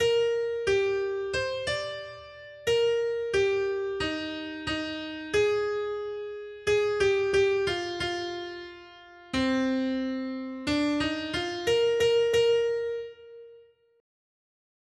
Noty Štítky, zpěvníky ol105.pdf responsoriální žalm Žaltář (Olejník) 105 Skrýt akordy R: Stvoř mi čisté srdce Bože! 1.